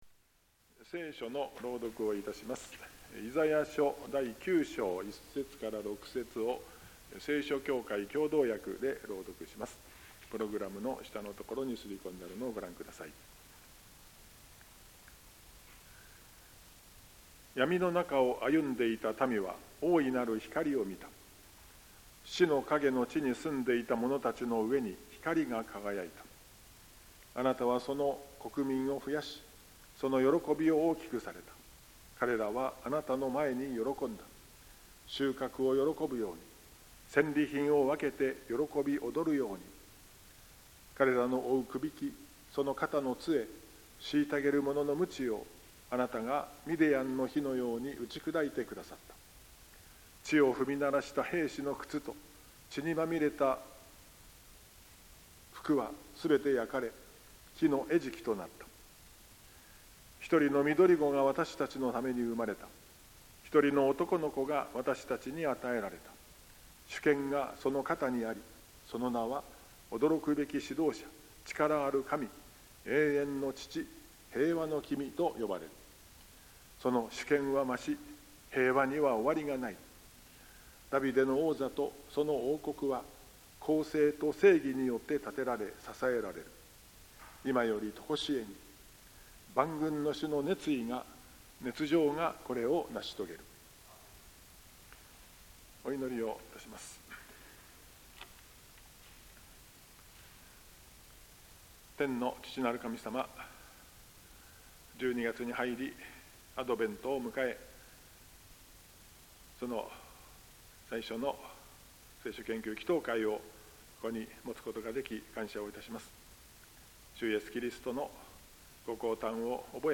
2025年12月奨励「一人のみどりごが私たちのために生まれた。」 （12月3日昼の聖書研究祈祷会）